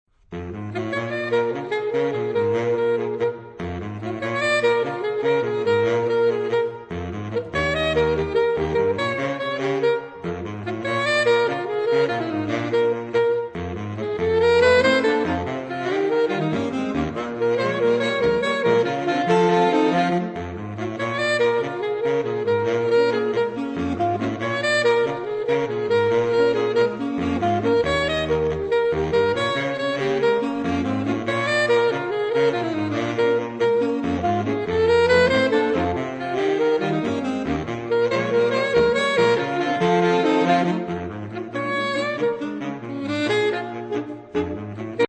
4 Saxophone (SATBar/AATBar) Obtížnost